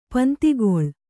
♪ pantigoḷ